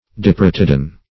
Search Result for " diprotodon" : The Collaborative International Dictionary of English v.0.48: Diprotodon \Di*pro"to*don\, n. [Gr. di- = di`s- twice + ? first + 'odoy`s, 'odo`ntos, tooth.]